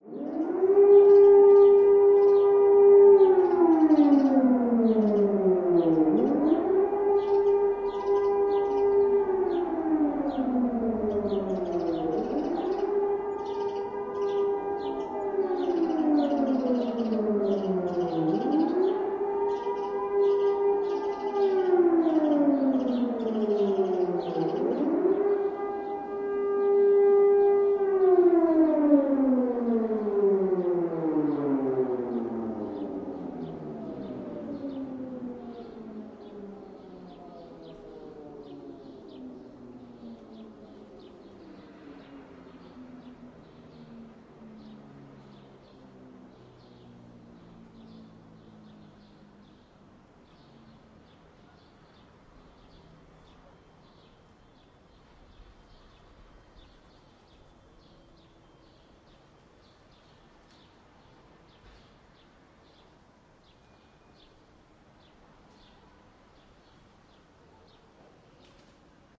警报器和警报器 " t128
描述：美国信号T128和联邦信号2001警报器在攻击模式下响起。这些警报器通常以稳定的音调响起，用于龙卷风警报，但这种上升和下降的音调将用于民事紧急情况，如空袭或其他对城市的攻击。
Tag: 警报器 出门预警 应急 空袭 警报器